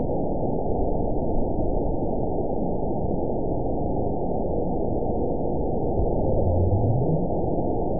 event 920241 date 03/08/24 time 20:34:51 GMT (1 year, 7 months ago) score 9.57 location TSS-AB01 detected by nrw target species NRW annotations +NRW Spectrogram: Frequency (kHz) vs. Time (s) audio not available .wav